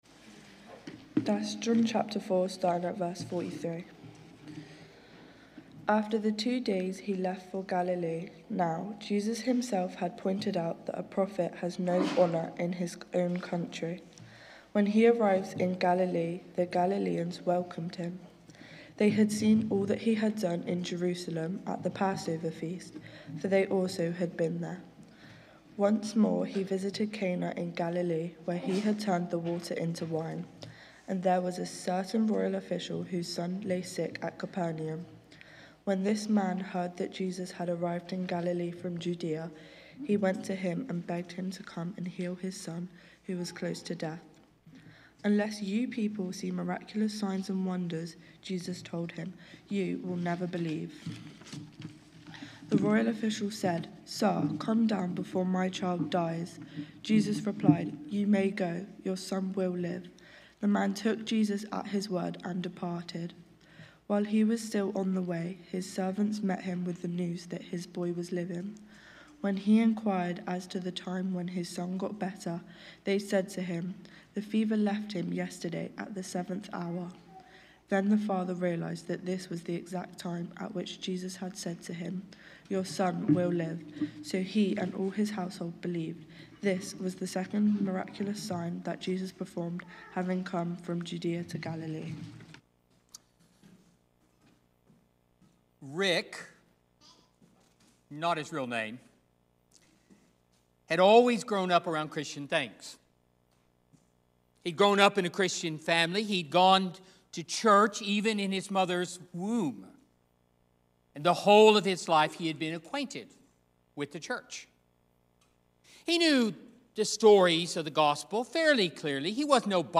Emmanuel Church Chippenham | Sermons